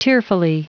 Prononciation du mot tearfully en anglais (fichier audio)
Prononciation du mot : tearfully